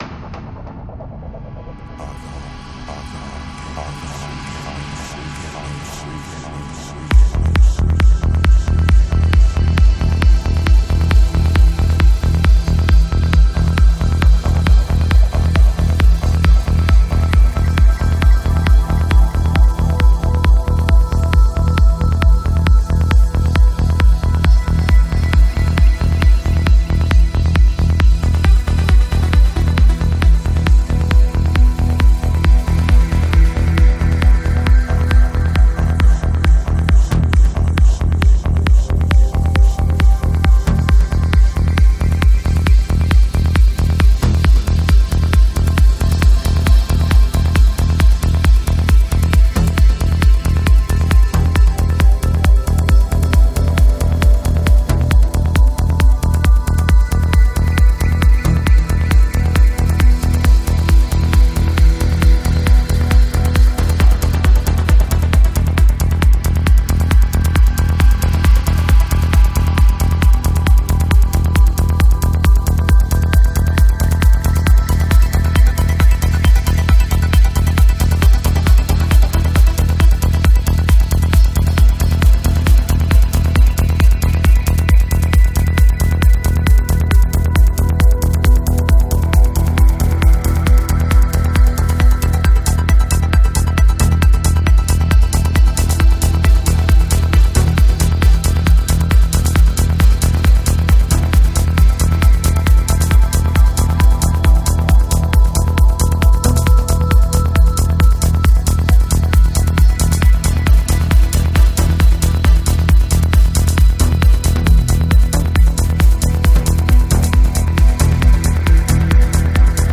Жанр: Psychedelic
Альбом: Psy-Trance